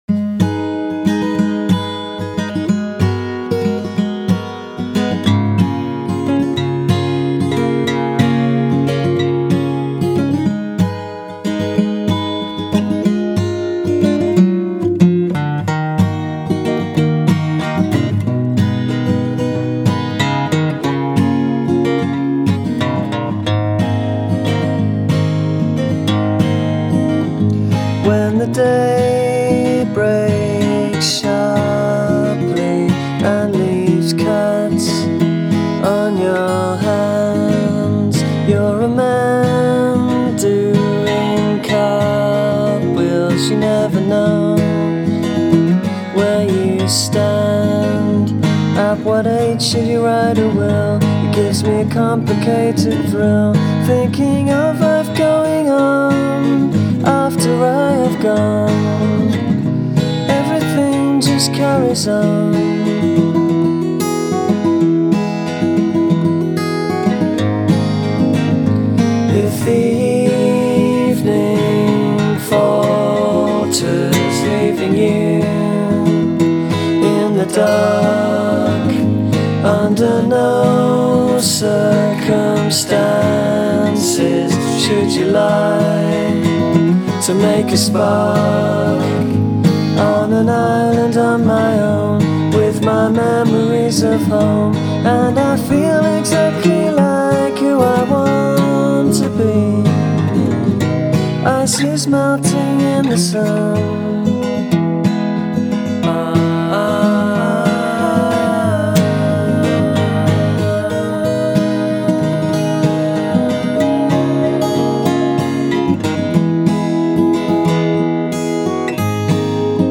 * Demo *